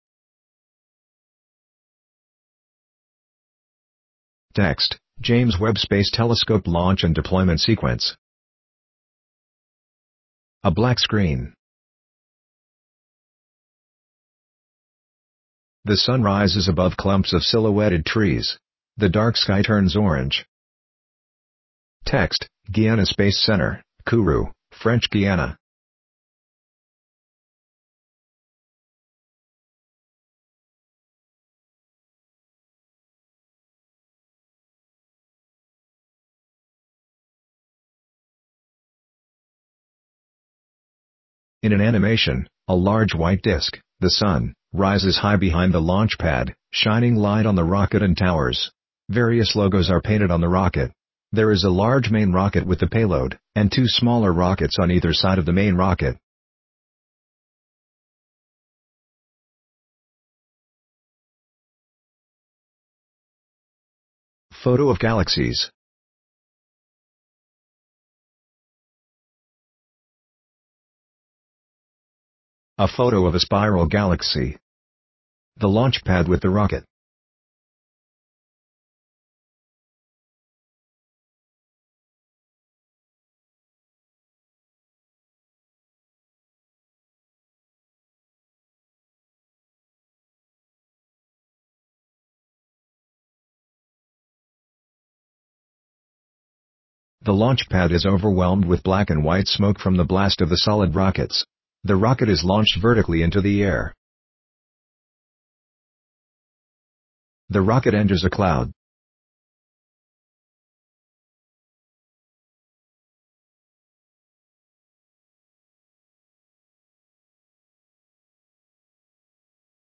Audio Description.mp3